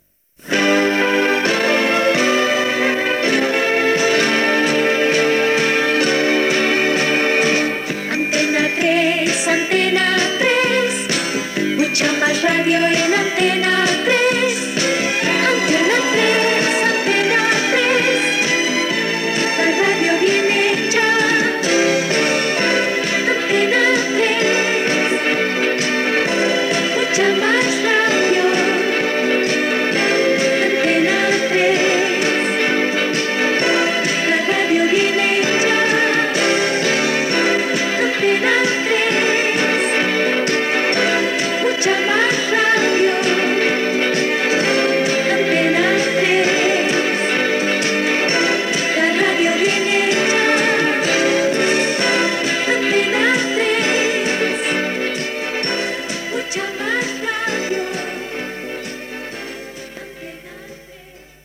Sintonia cantada de l'emissora